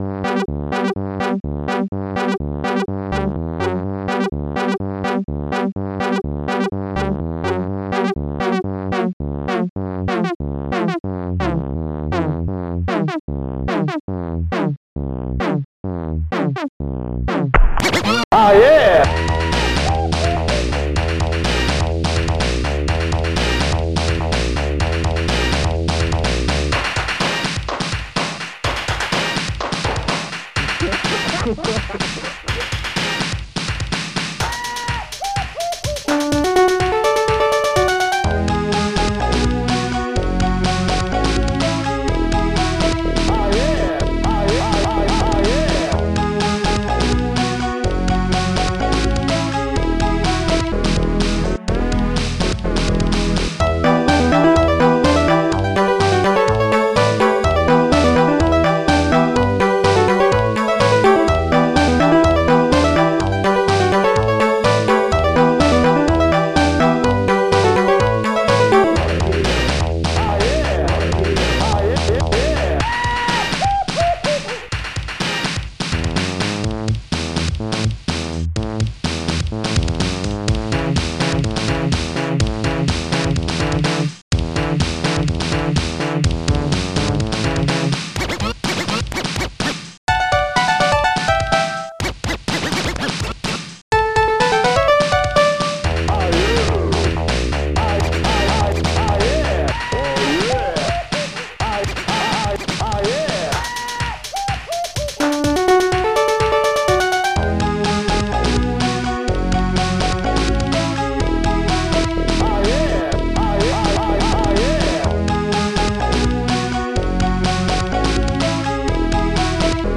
Protracker and family
st-01:monobass
st-01:ringpiano
st-01:horns
st-01:strings7